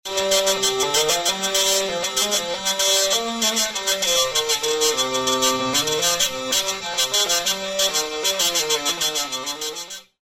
La vielle est conçue comme instrument amplifié sans aucune résonnance propre. Le son est capté par des senseurs electromagnétiques (donc cordes en métal obligatoires).
Le son dépend bien entendu de l'amplification du signal des capteurs et peut être varier au moyen d'un égalisateur.